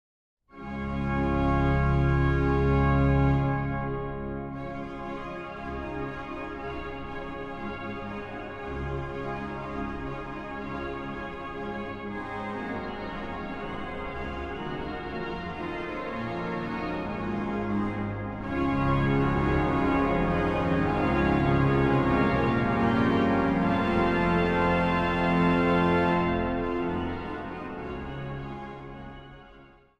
Zang | Samenzang